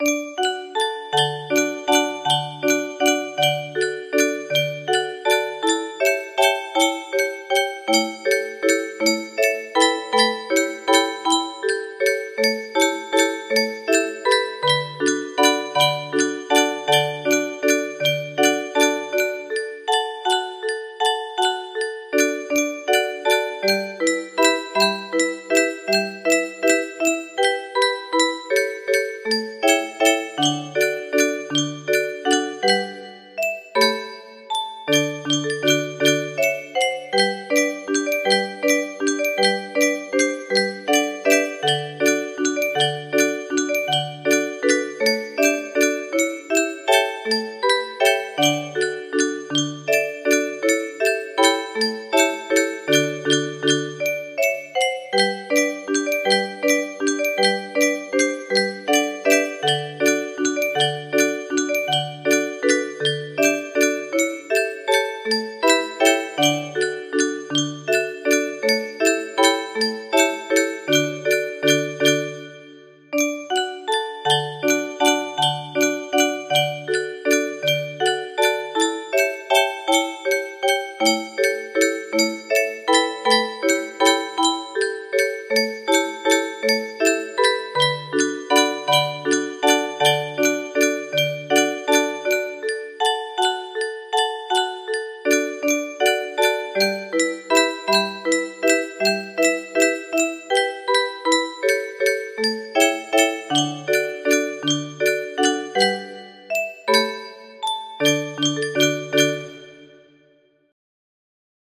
BlueDanube30notemusicbox music box melody